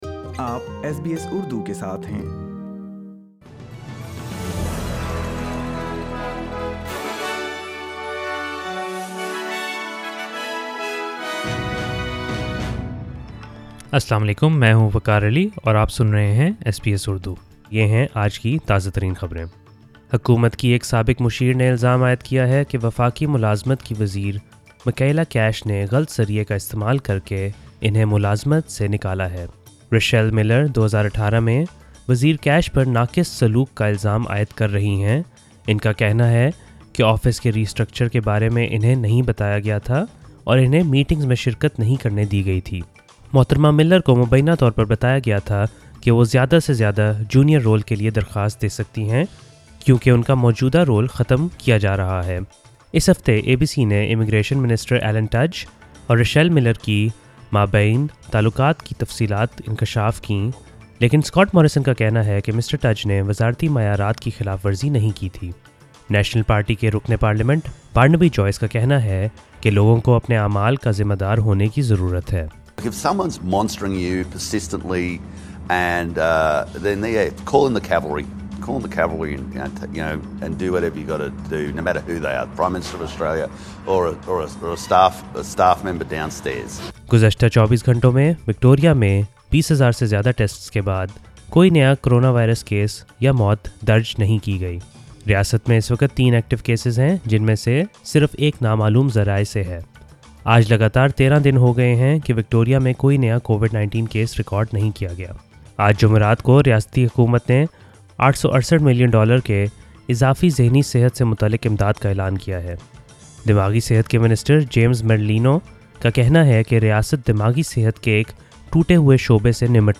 ایس بی ایس اردو خبریں 12 نومبر 2020